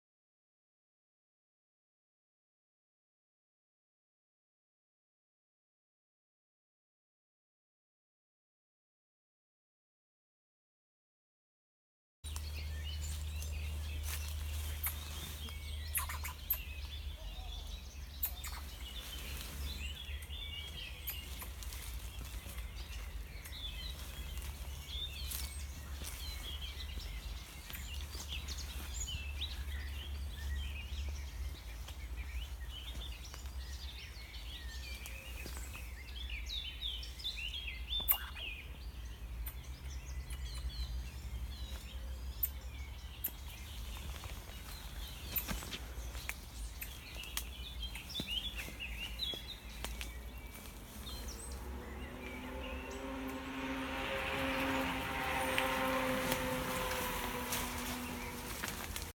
Eichhörnchen:
eichhocc88rnchen.mp3